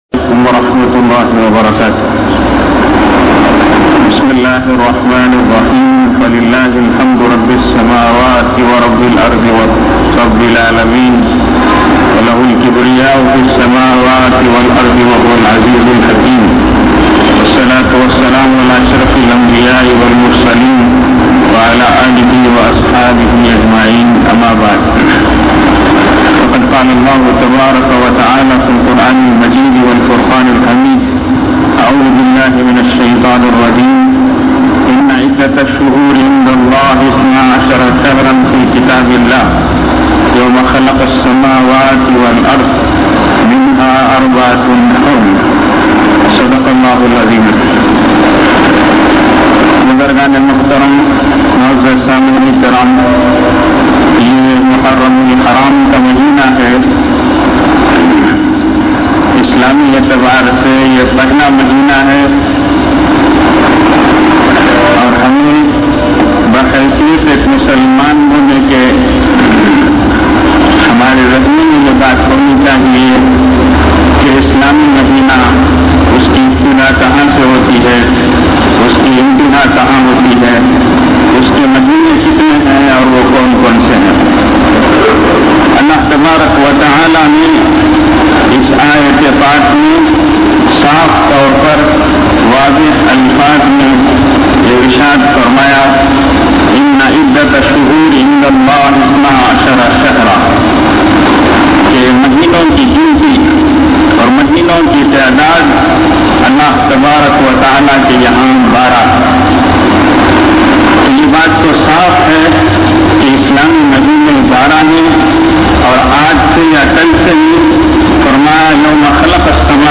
Juma Lectures